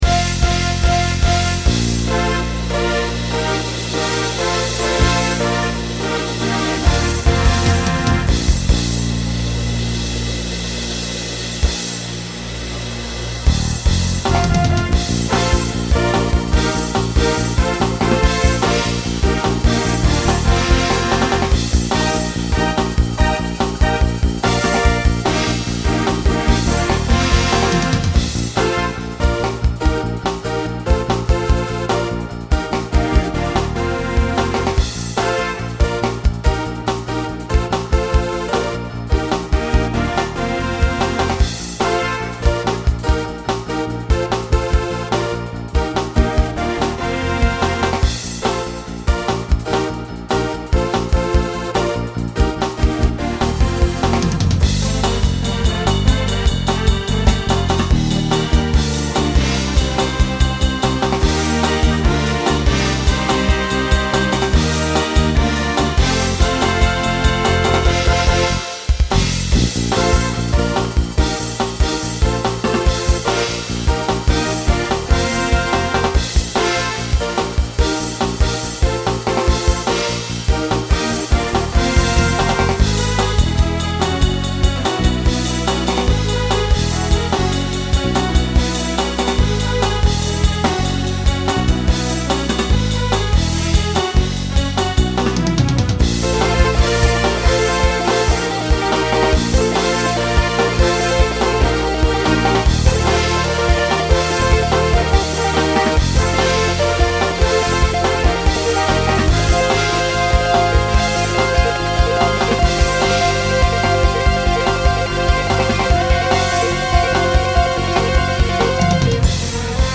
SATB Instrumental combo
Rock